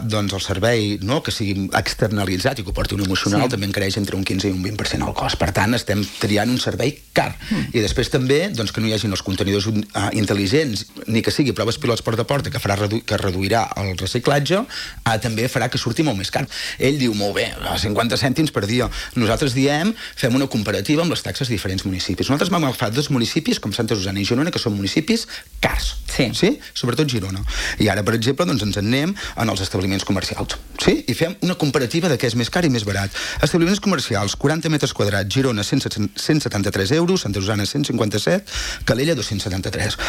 Moment de l'entrevista d'ahir